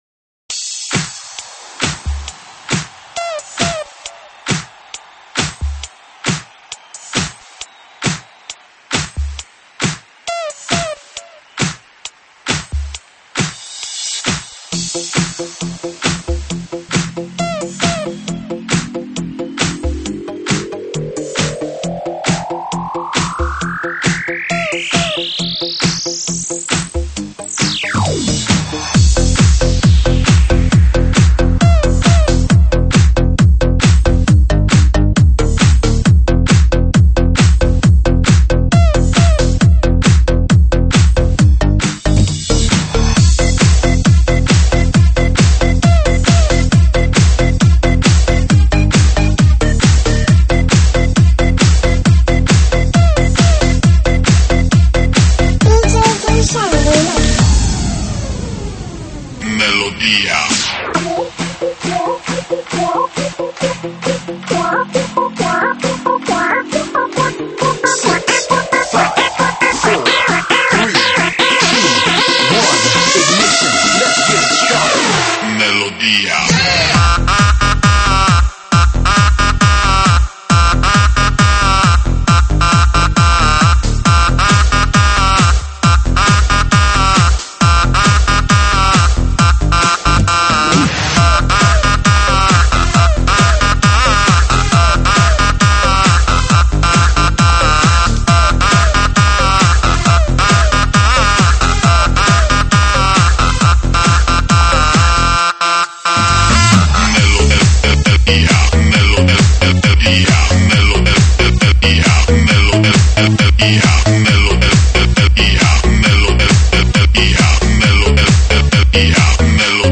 车载大碟
舞曲类别：车载大碟